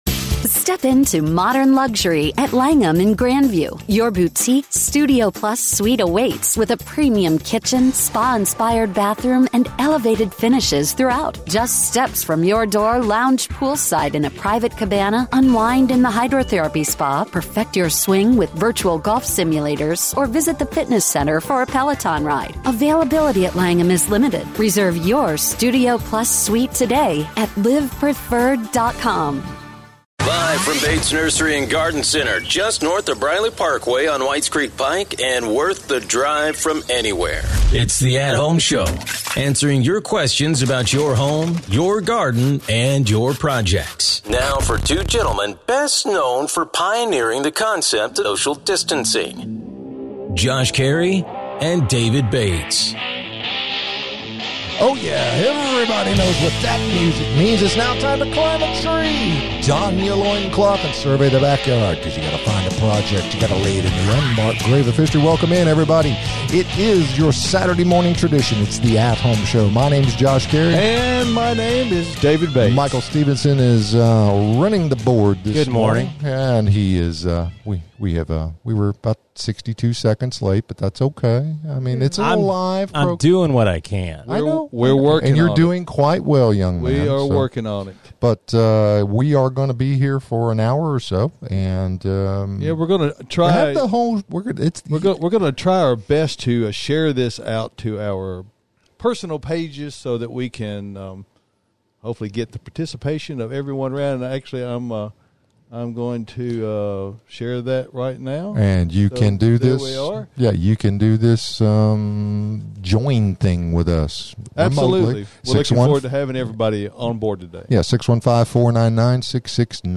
Plus, your phone calls, text and comments!